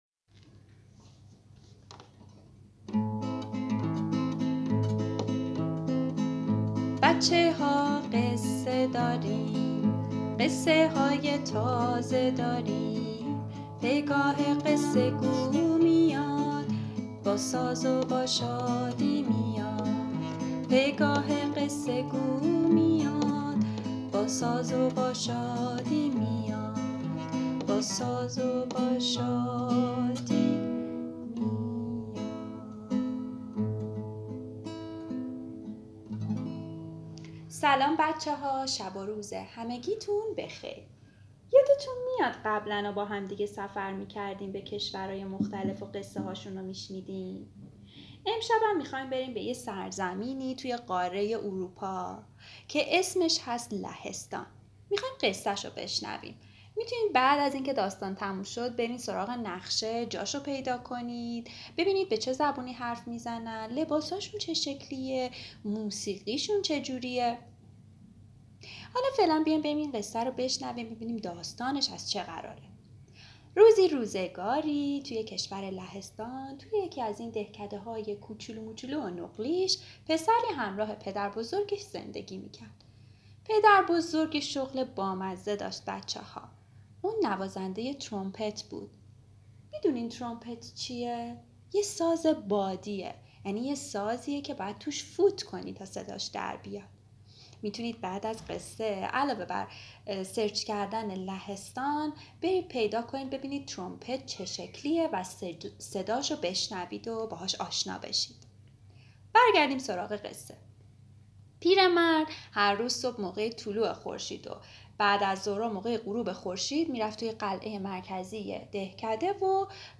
قصه صوتی کودکان دیدگاه شما 2,379 بازدید